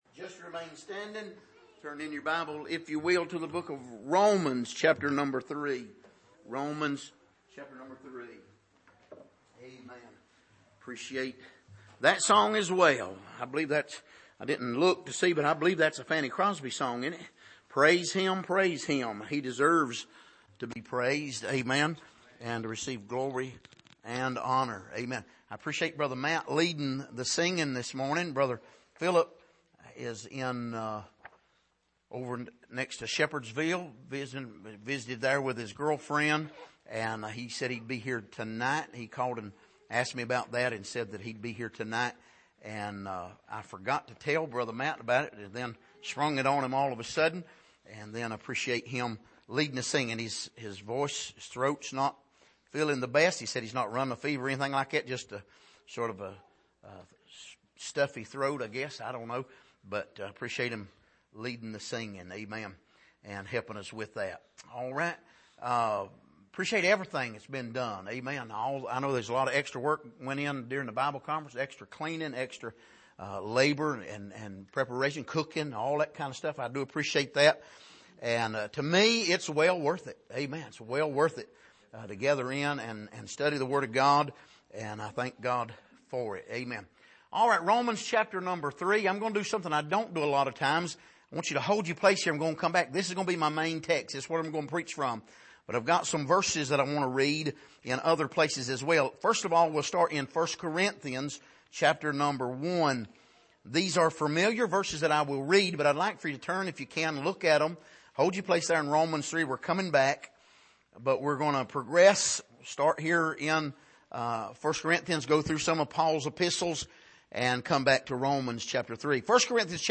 Passage: Romans 3:19-31 Service: Sunday Morning